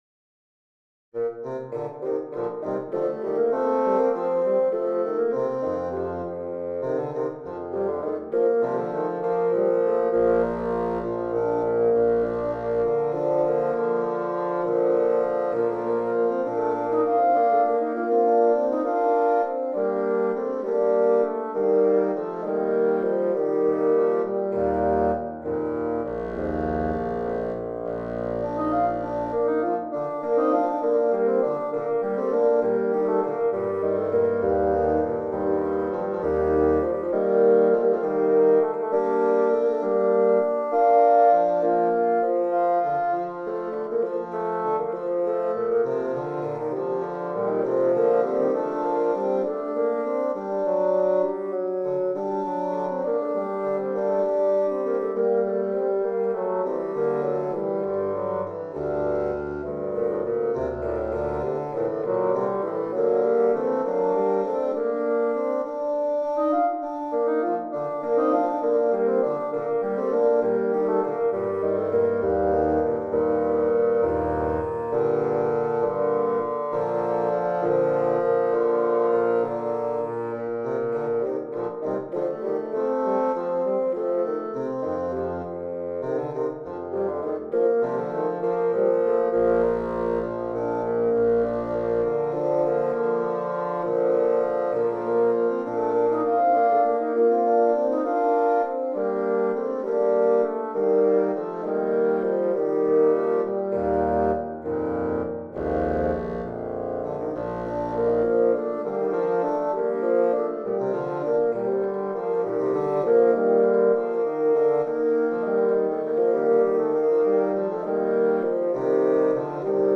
Of musical tidbits, this little trio for bassoons was sketched for the delight of these voices singing together, in homophony, in canon and counterpoint and with some humor as well. The opening of five sections is in 6/8 and a strict canon.
What follows is an allegro, one of two, in 3/4, with some chromatic gestures in the lower register.
Another allegro, an imitative adagio, and then a final quasi homophonic presto.
10 pages, circa 9' 00" - an MP3 demo is here: